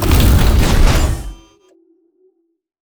attack2.wav